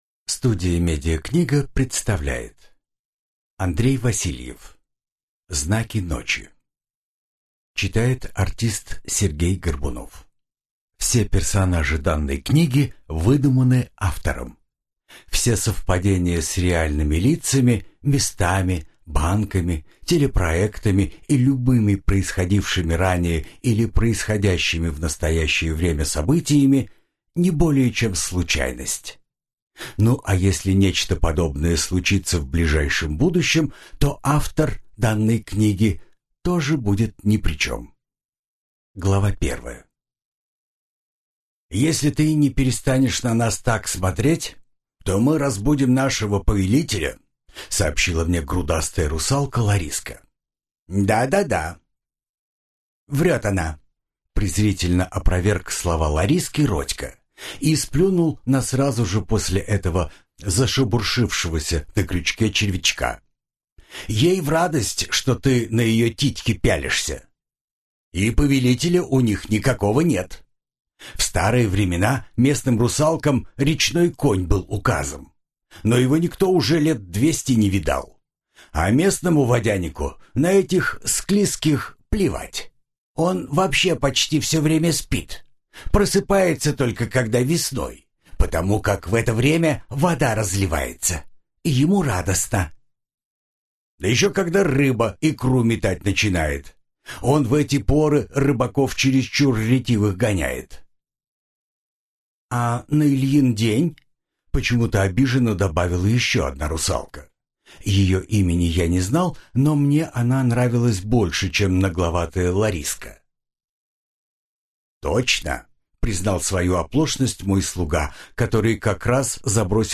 Аудиокнига Знаки ночи | Библиотека аудиокниг
Прослушать и бесплатно скачать фрагмент аудиокниги